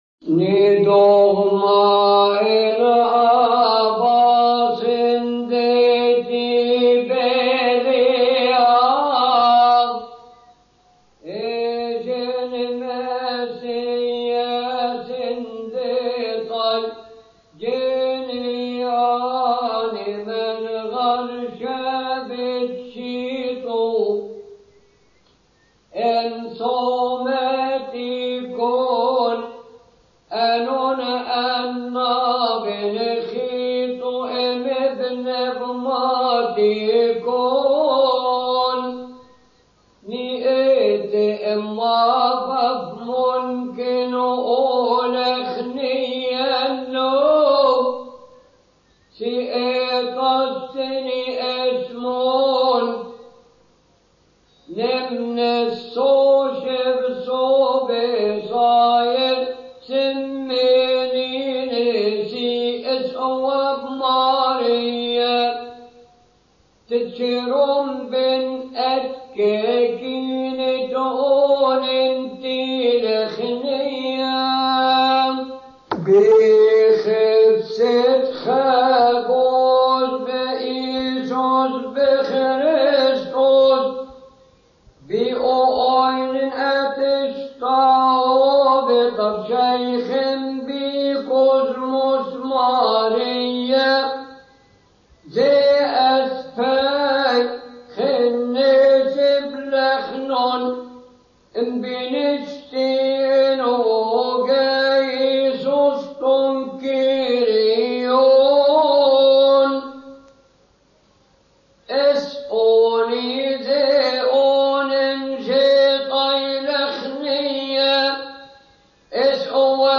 المرتل